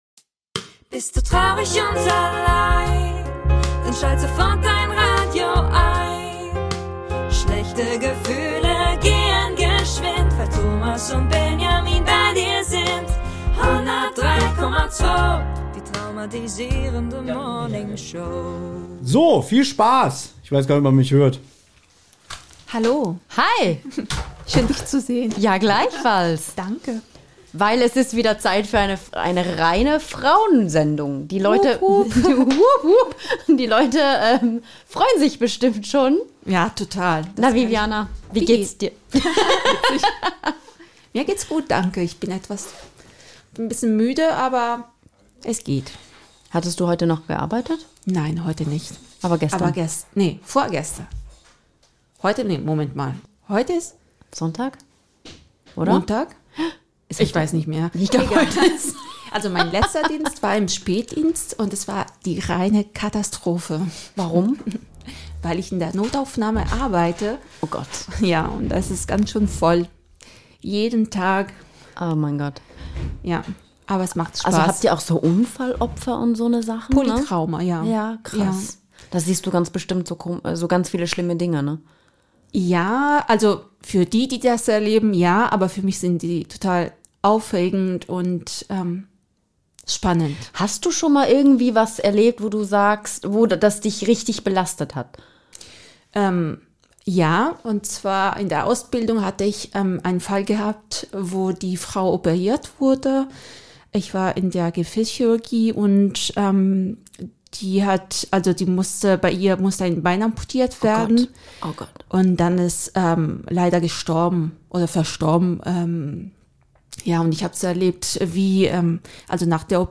Treffen sich eine Italienerin und eine Schweizerin im Hauptstadtstudio.